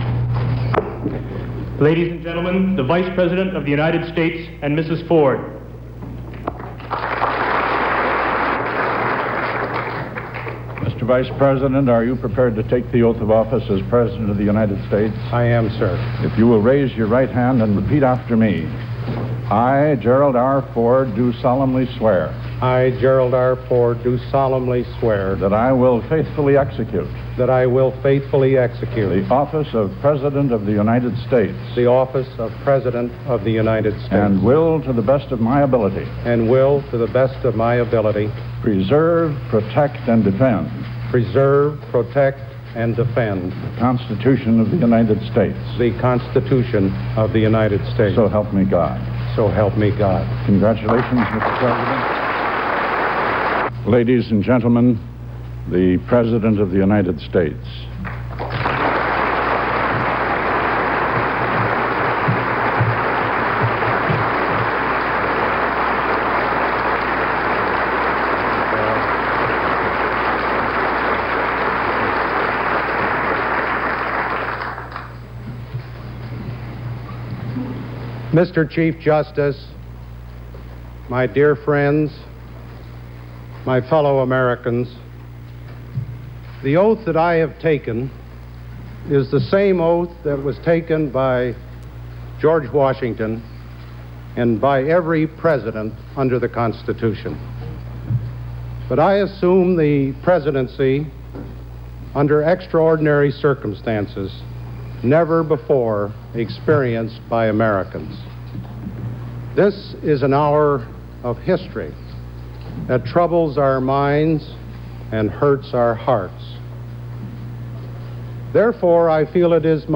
Broadcast on CBS-TV, August 9, 1974.